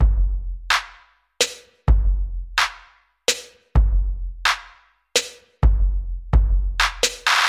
标签： 128 bpm Ethnic Loops Drum Loops 1.26 MB wav Key : Unknown
声道立体声